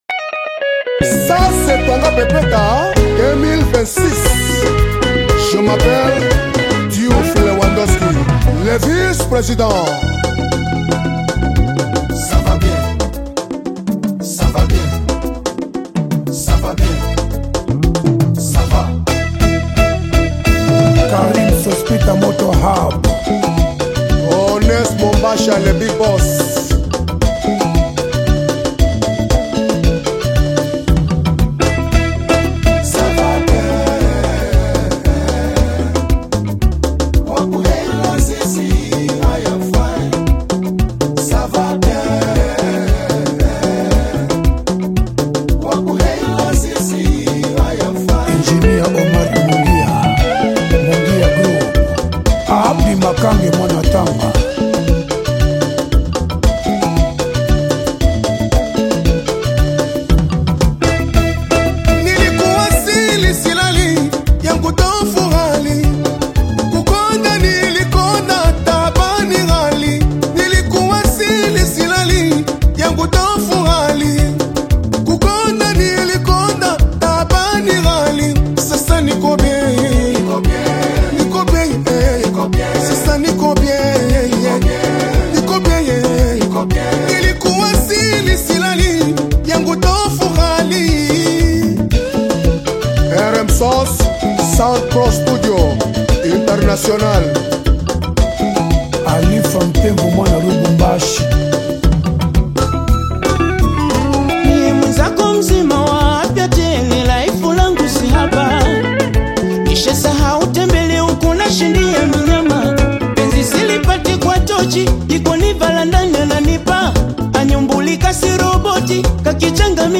spirited Afro-Pop single
Genre: Rhumba